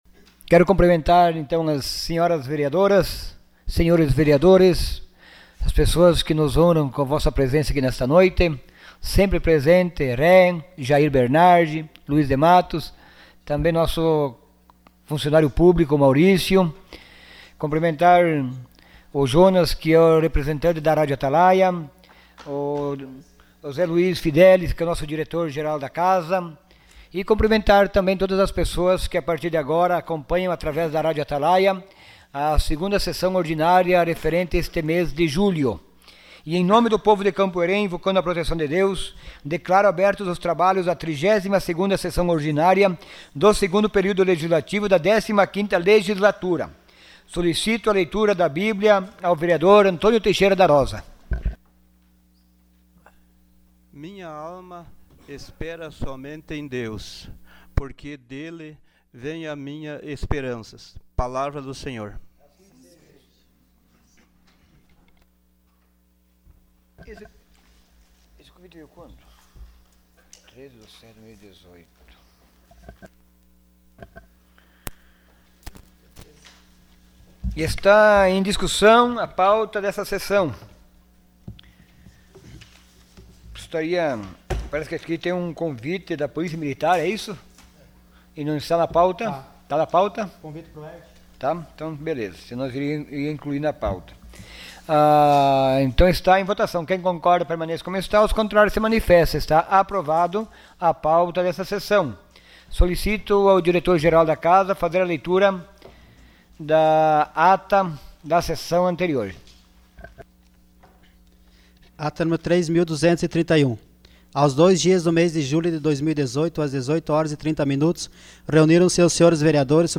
Sessão Ordinária dia 05 de julho de 2018.